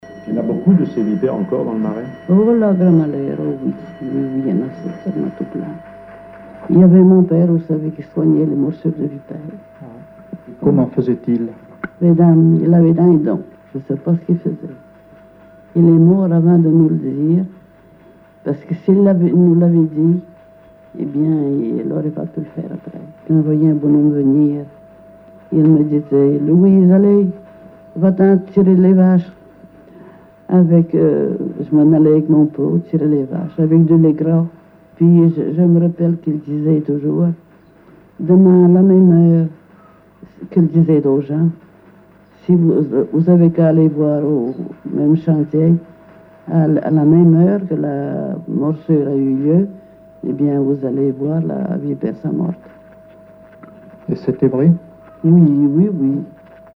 témoignage sur les fréquentations amoureuses
Catégorie Témoignage